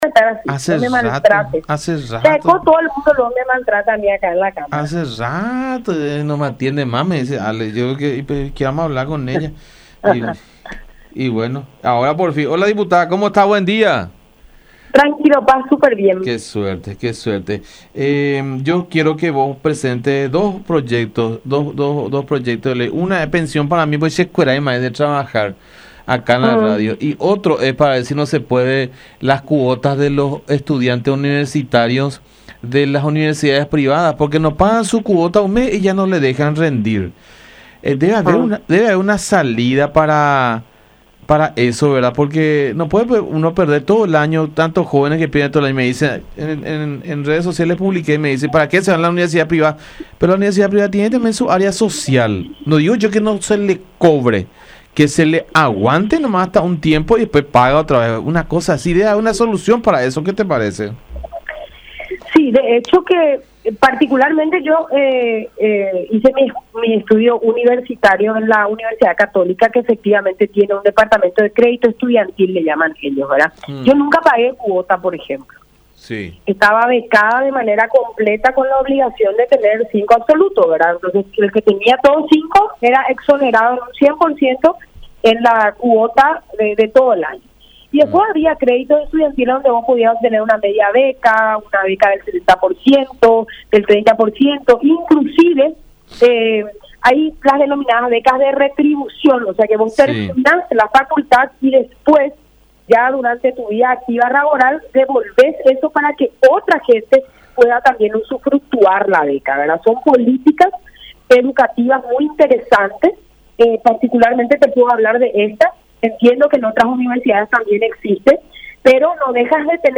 “Yo no cuestiono los méritos como futbolista, sino la forma en la que llegó a esta pensión”, criticó González en comunicación con La Unión.